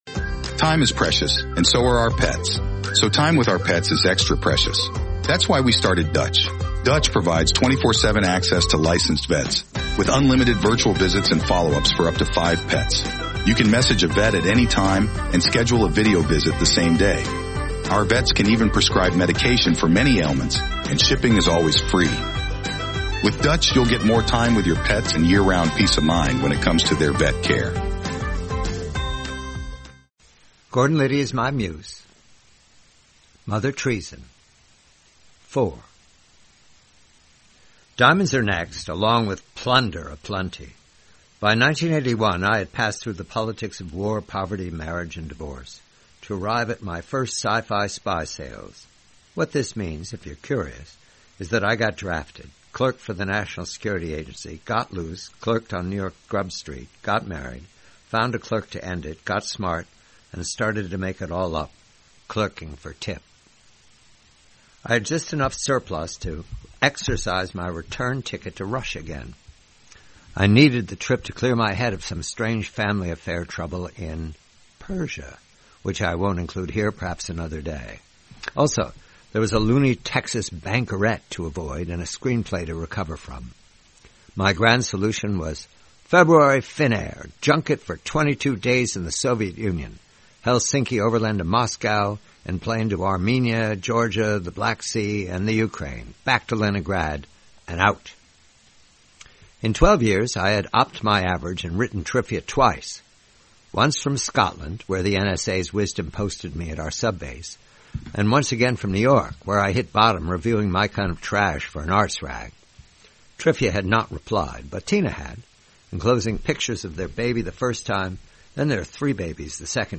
4/7: "Mother Treason," a story from the collection, "Gordon Liddy Is My Muse," by John Calvin Batchelor. Read by John Batchelor.